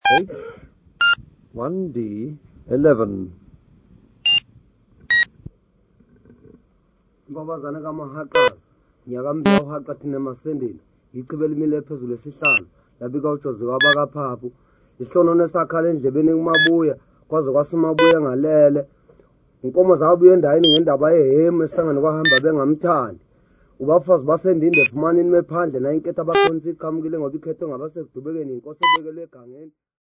Folk music--Africa
Field recordings
sound recording-musical
Praise chants for the chief.
96000Hz 24Bit Stereo